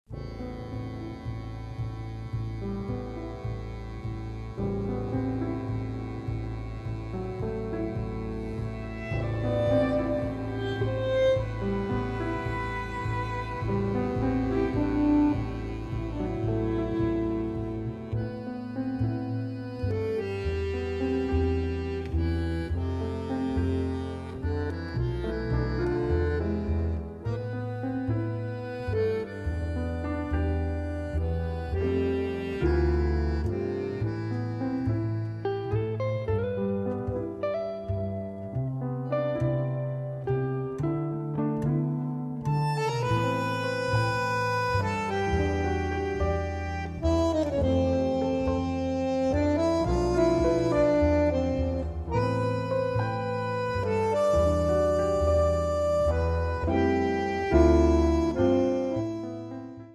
bandoneon
pianoforte
violino
chitarra
basso
Mai freddo, mai alchemico seppur tecnicamente perfetto.